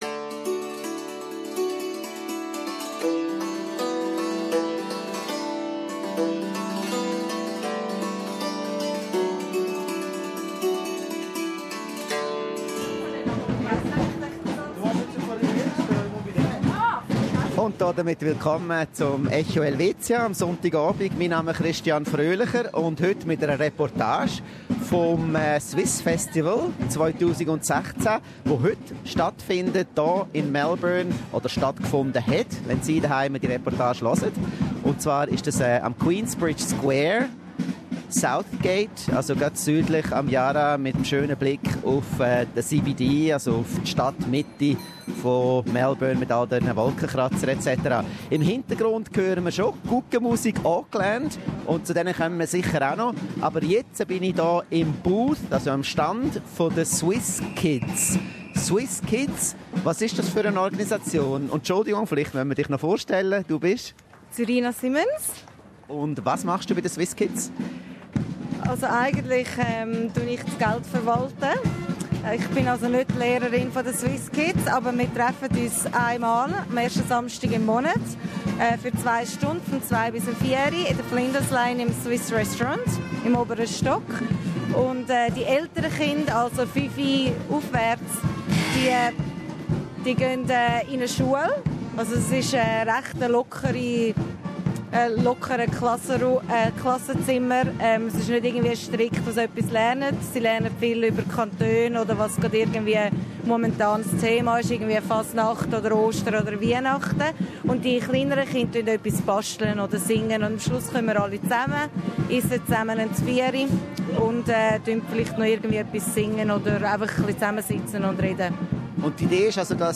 But those brave enough to front the ferocious winds, were rewarded with culinary, musical and cultural delights. The Swiss Festival 2016 at Queensbridge Square in Melbourne is history - time to listen to our SBS live report (9 October) and SBS second harvest (16 October), for a total of 30 minutes of Swiss bliss!